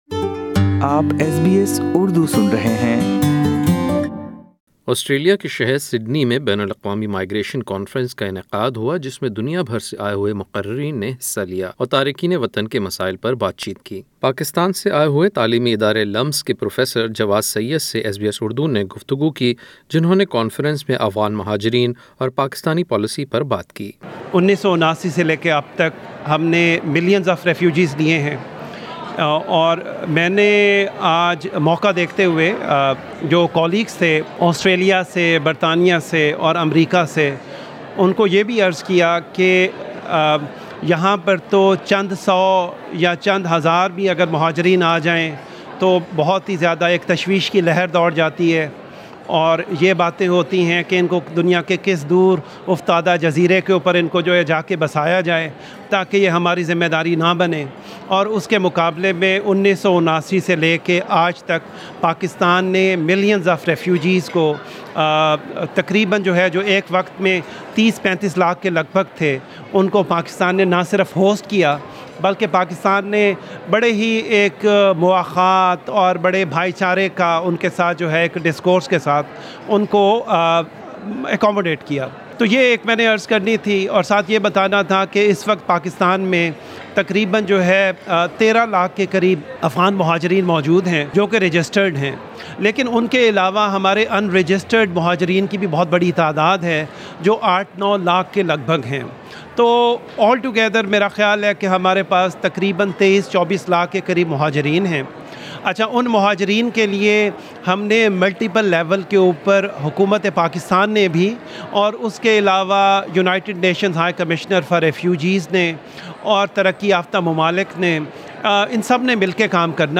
خصوصی گفتگو